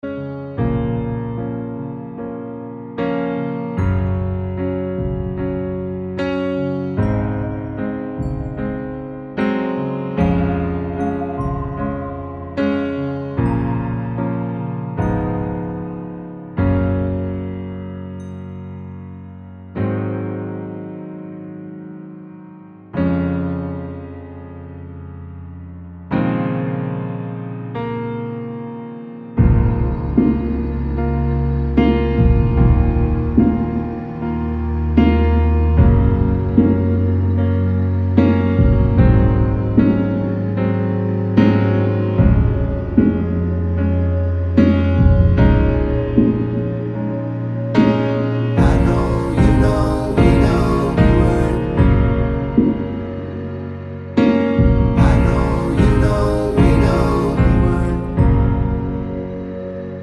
Acoustic in Female Key Pop (2020s) 3:43 Buy £1.50